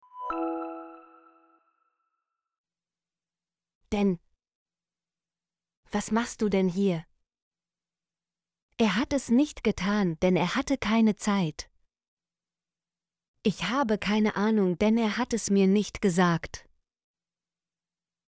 denn - Example Sentences & Pronunciation, German Frequency List